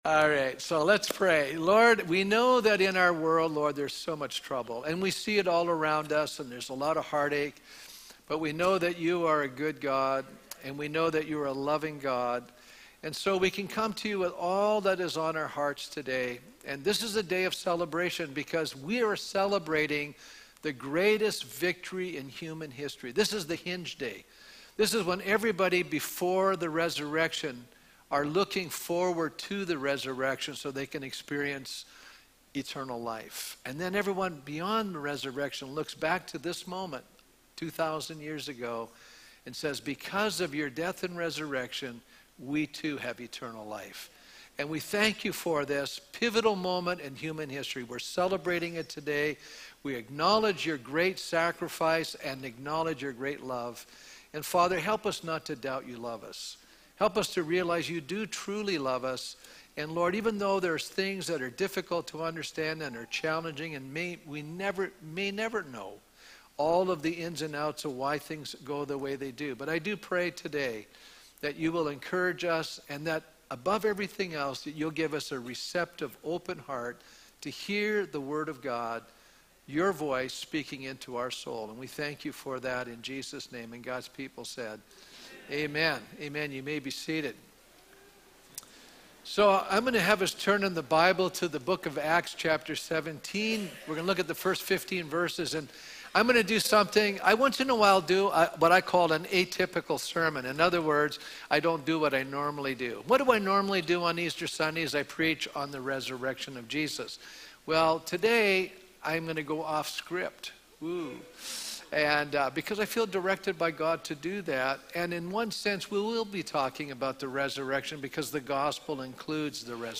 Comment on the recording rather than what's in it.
Easter Sunday